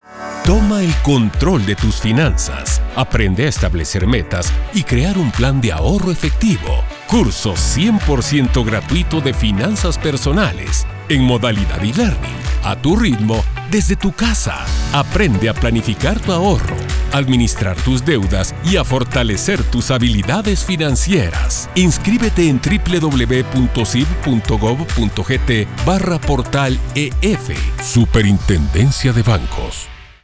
Anuncios en Radio